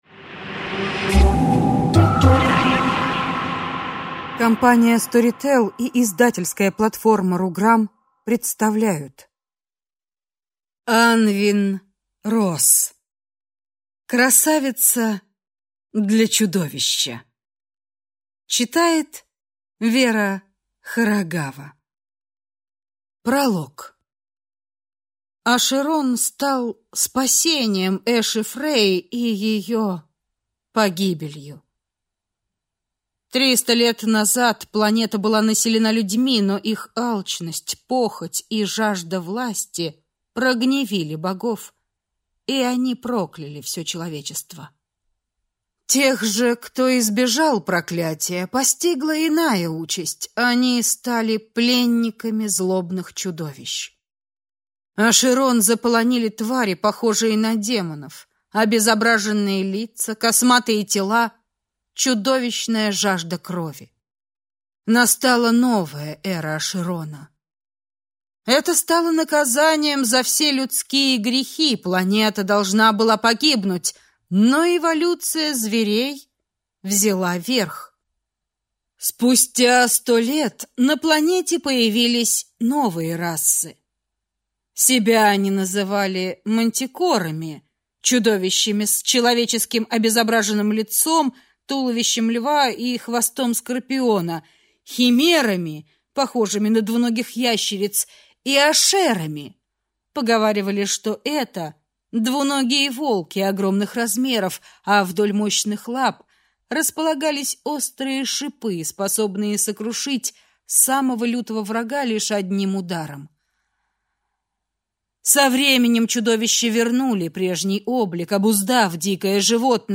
Аудиокнига Красавица для чудовища | Библиотека аудиокниг